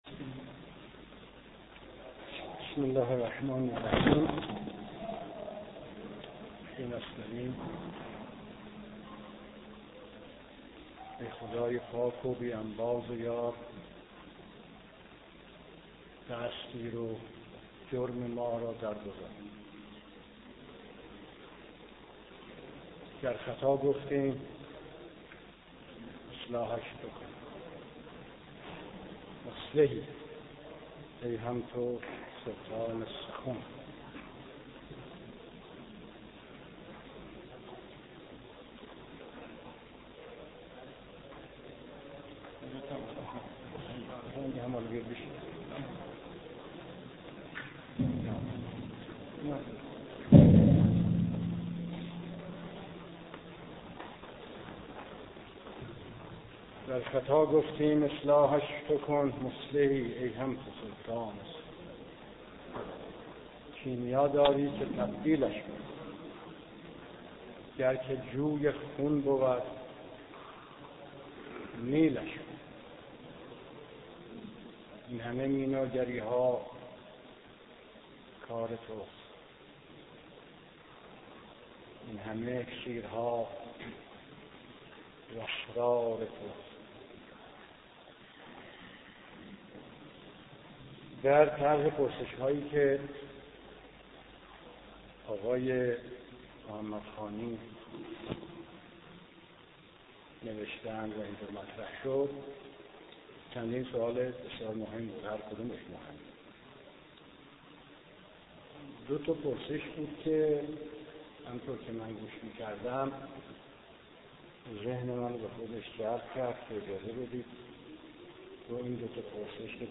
سخنرانی دکتر ابراهيمی ديناني در همایش" نيايش؛ ضرورت زندگي" است.مرداد 86.